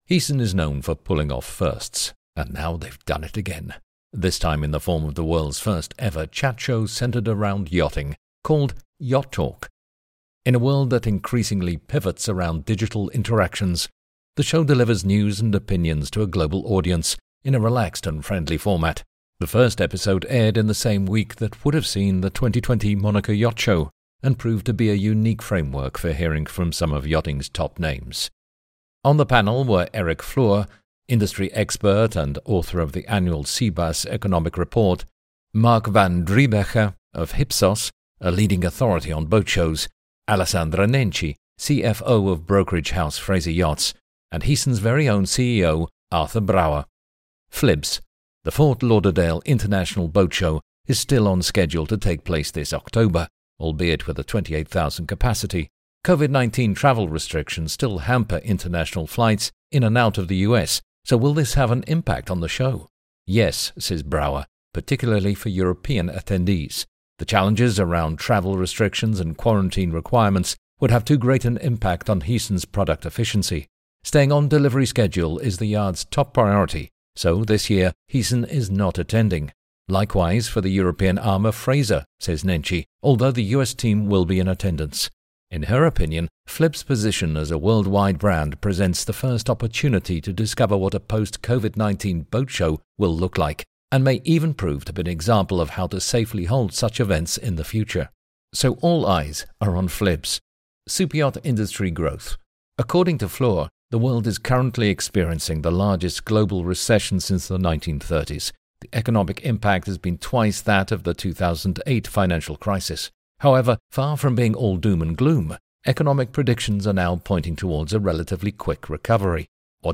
This time in the form of the world’s first ever chat show centred around yachting, called YachtTalk.
In a world that currently pivots around digital interactions, the show delivers news and opinions to a global audience in a relaxed and friendly format.